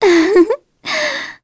Cassandra-vox-trs_01.wav